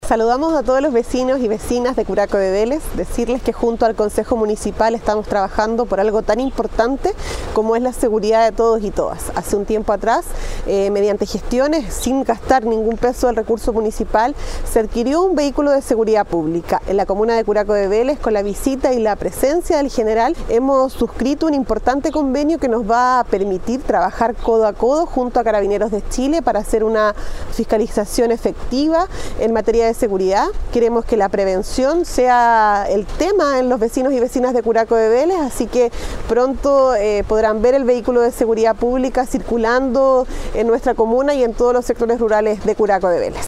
Al respecto la alcaldesa de la comuna Javiera Yañez, indicó: